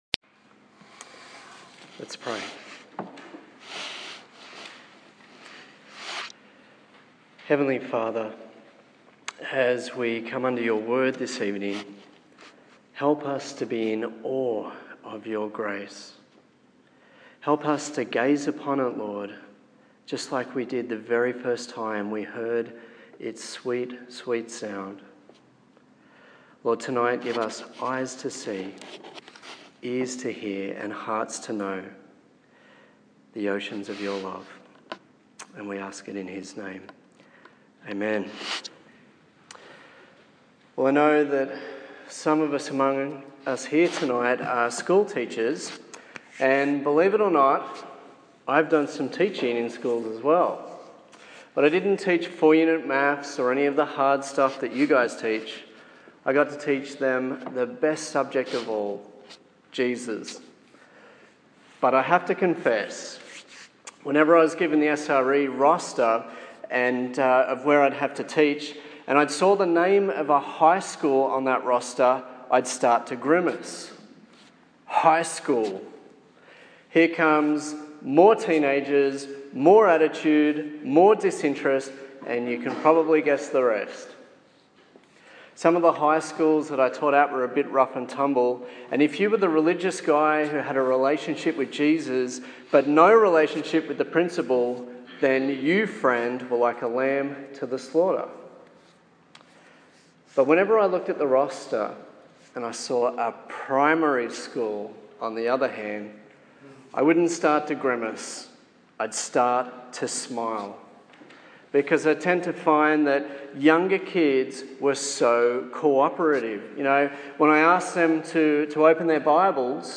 His Glory Preacher